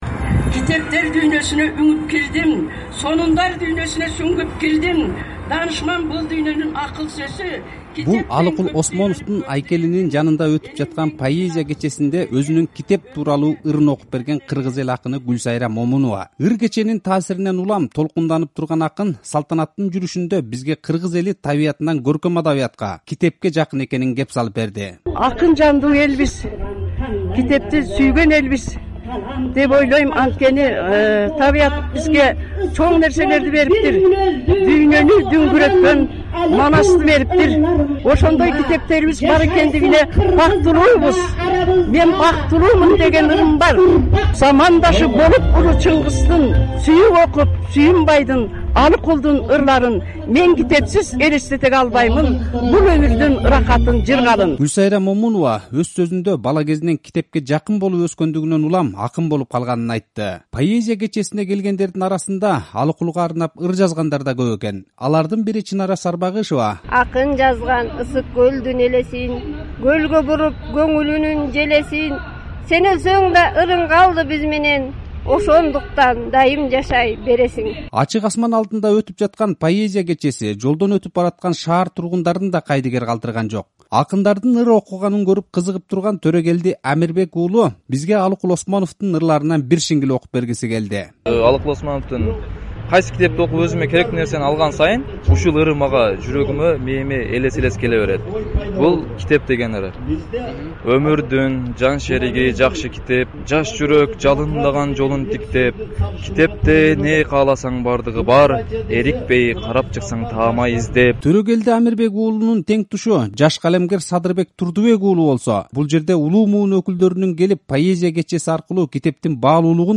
Ачык асман алдында өткөн поэзия кечеси